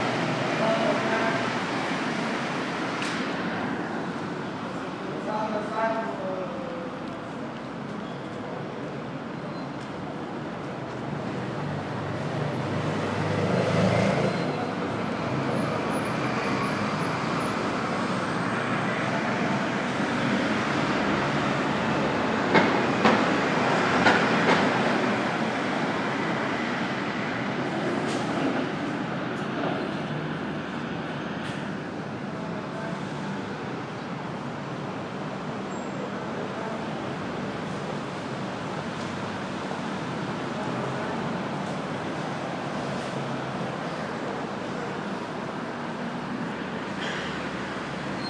Dawn Chorus